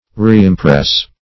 Reimpress \Re`im*press"\ (-pr?s")